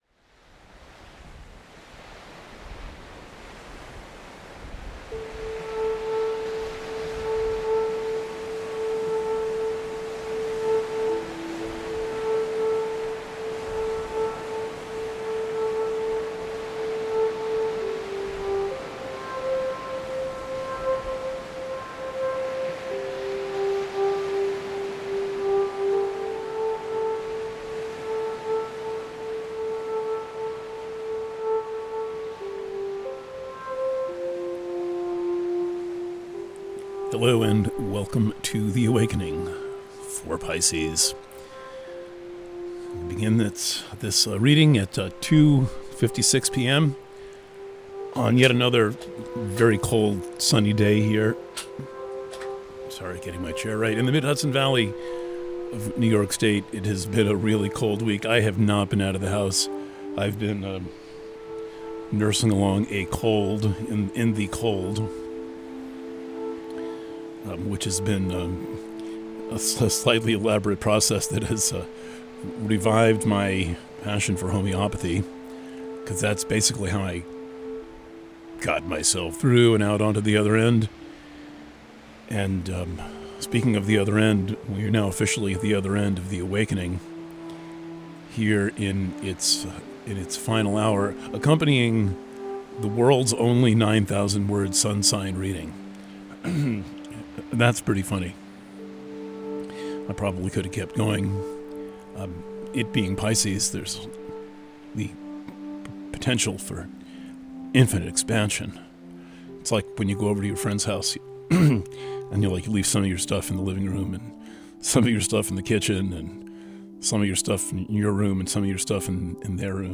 Preview – The Awakening for Pisces Purchasing options for The Awakening Preview – Written reading Preview – The Awakening for Pisces – PDF Preview – Audio reading Alternate Player (Audio Only) Views: 154